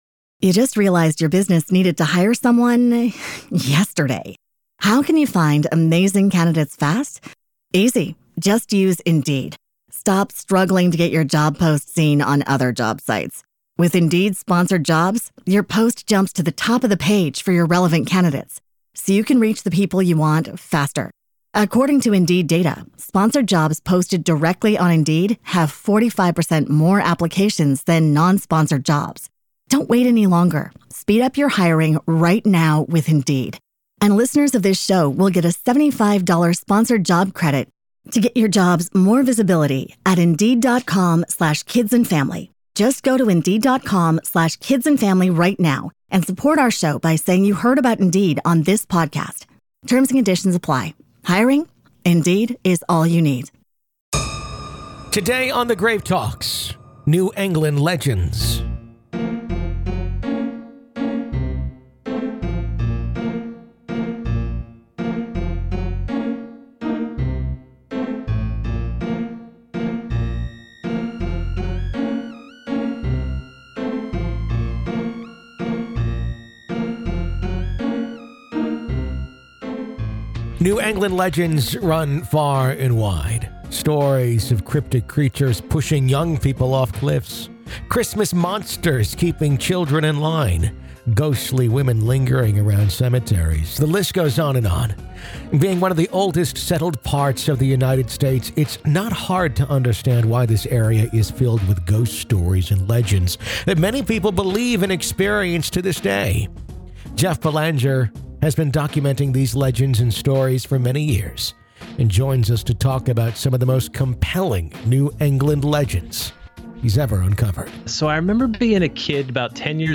Along the way, hear field recordings of phantom footsteps, learn the best (or worst) nights to visit haunted bridges, and discover why folklore may hold more truth than we dare admit.